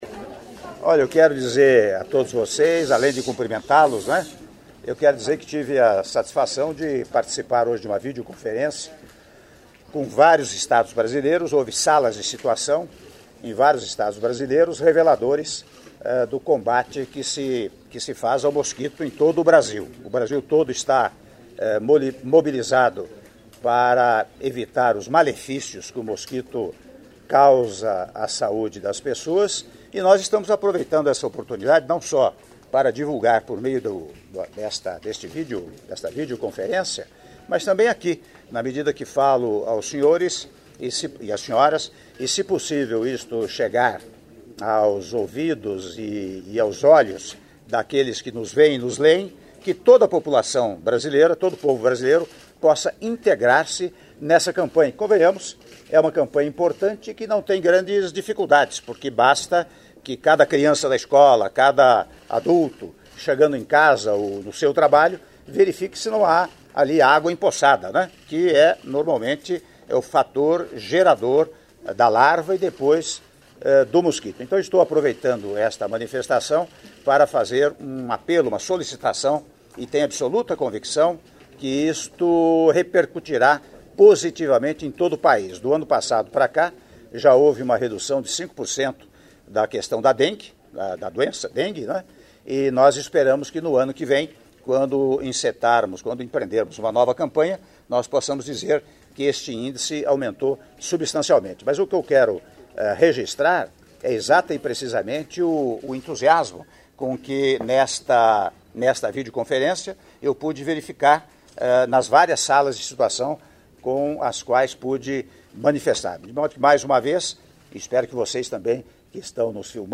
Áudio da entrevista do presidente da República, Michel Temer, após realização de videoconferência com estados na campanha contra o Aedes Aegypti - Brasília/DF (02min05s)